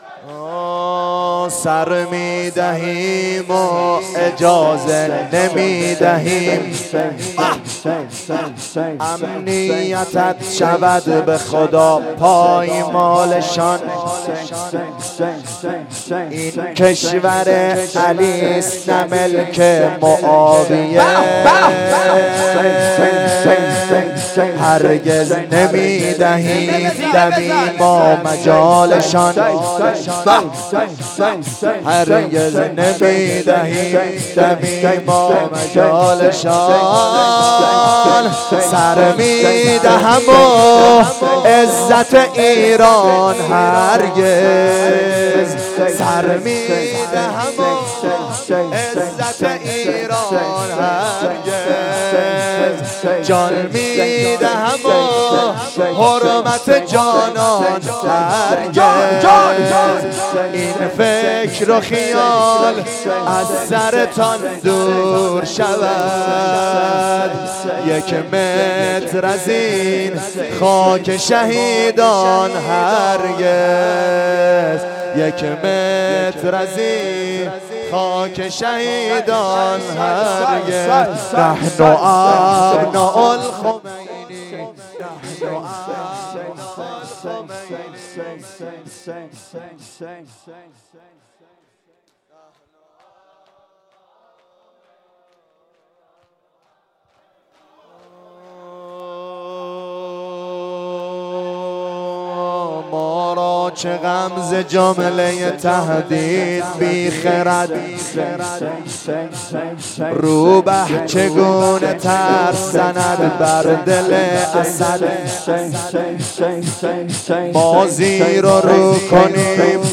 شور 1